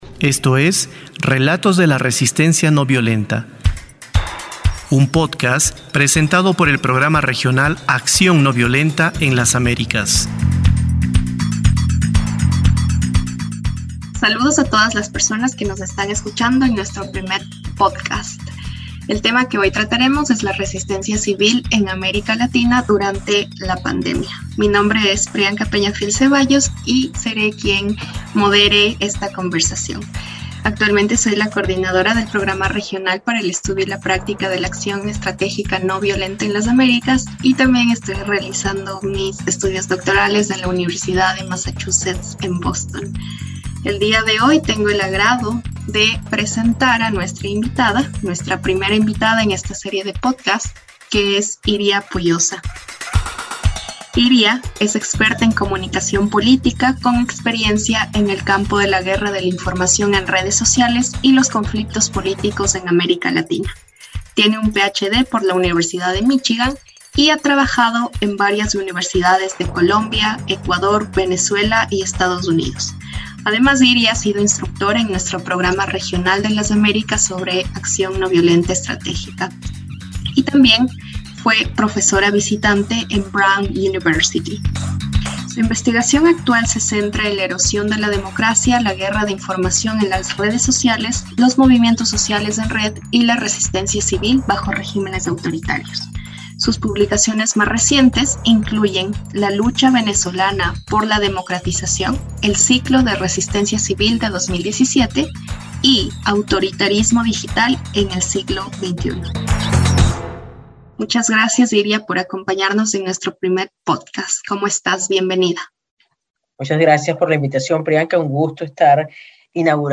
Tamaño: 23.05Mb Formato: Basic Audio Descripción: Entrevista - Acción ...